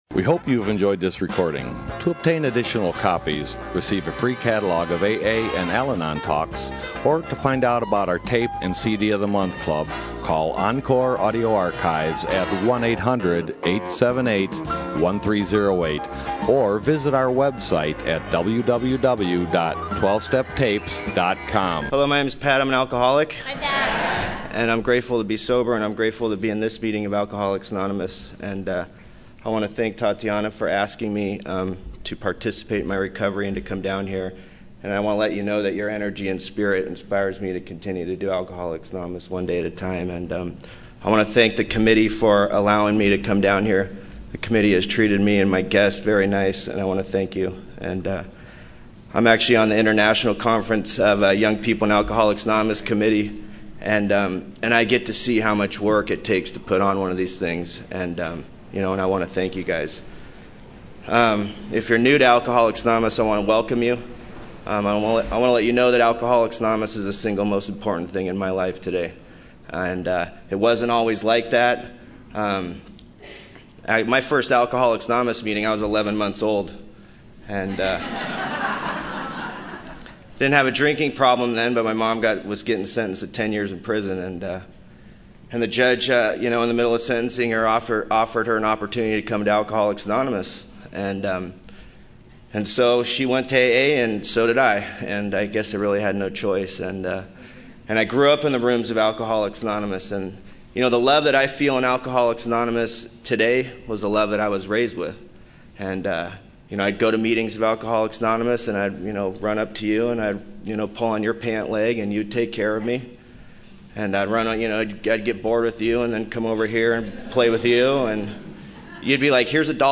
San Diego Spring Roundup 2007